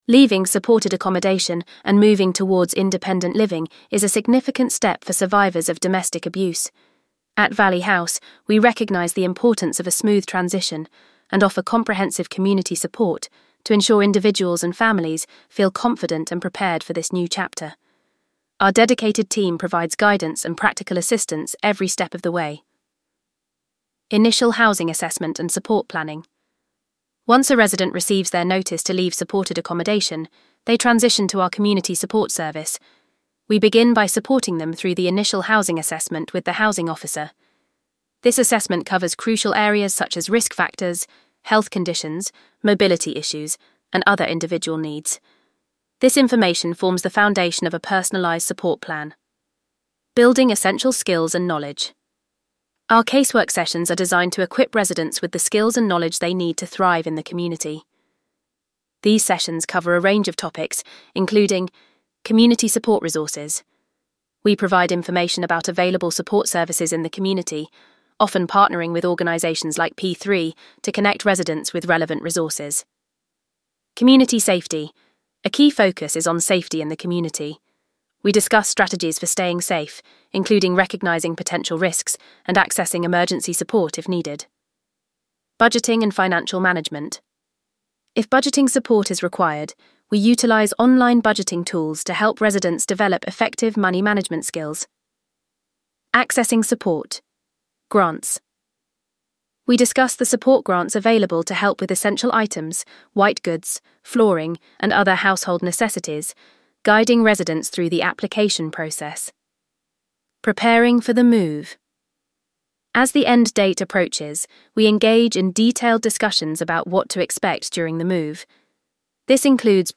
VOICEOVER-Housing-Support.mp3